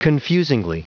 Prononciation du mot confusingly en anglais (fichier audio)
Prononciation du mot : confusingly